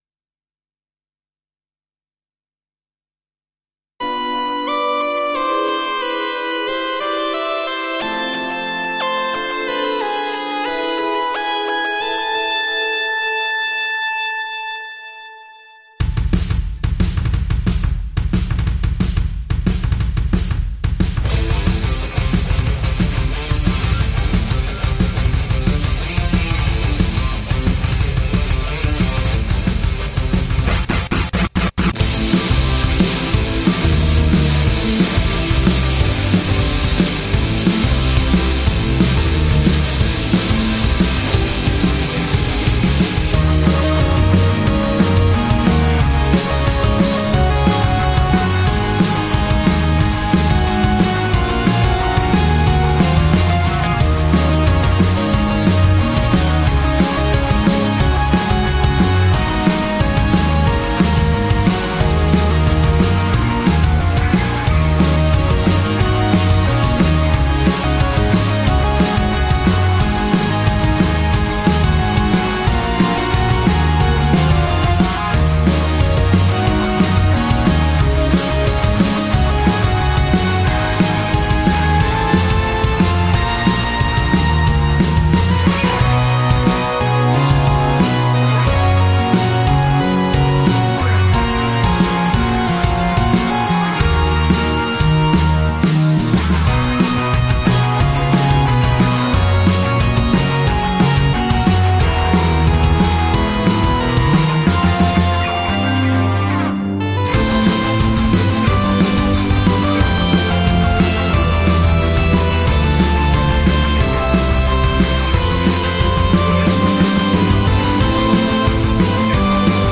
・ママさんに合わせてハードロック
メインは圧がほしかったのでギターとサチュレーション挿したシンセで音圧を稼いでます。
Aメロはいきなり転調
Bメロ少し明るめ
なんだか明るめになっちゃいました
Cメロ　完全にラスサビへつなげる為の助走
アウトロがまだなので未完成ではありますが
それにしても久しぶりにハードロック作ったなぁ